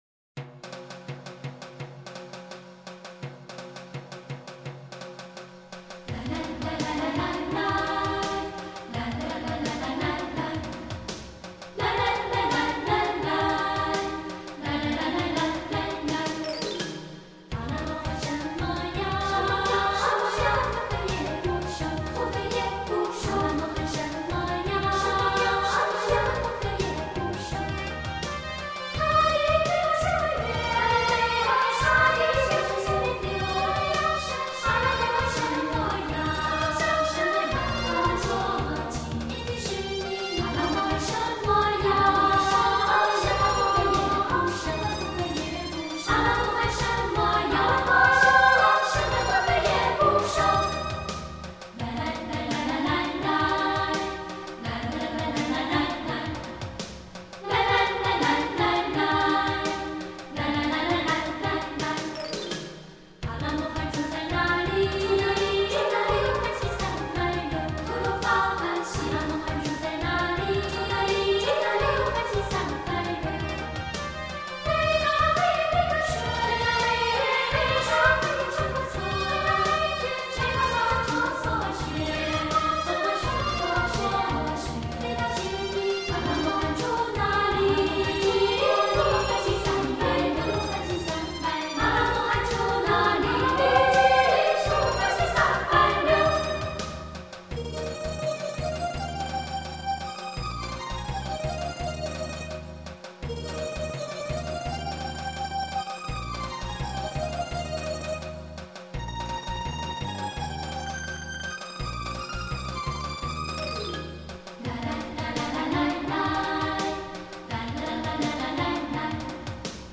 充满边疆风情的新疆、藏族、哈萨克….情歌，带您飞入不同的爱情国度。